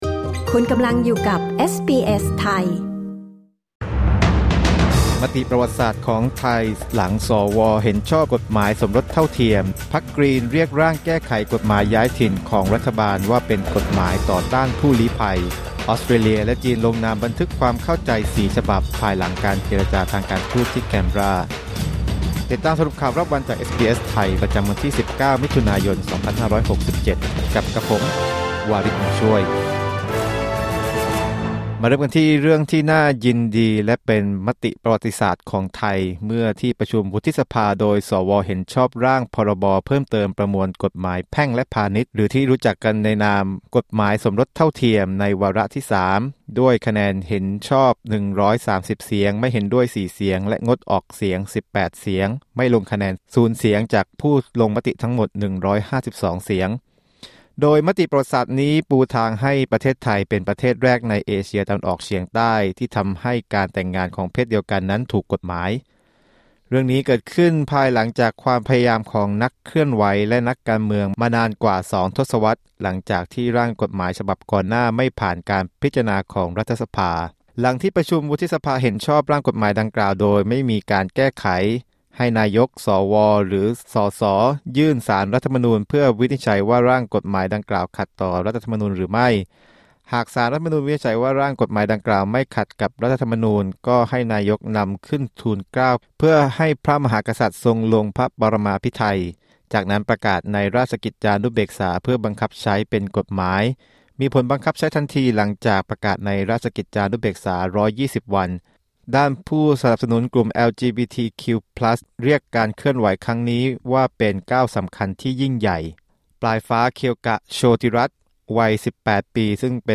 สรุปข่าวรอบวัน 19 มิถุนายน 2567
คลิก ▶ ด้านบนเพื่อฟังรายงานข่าว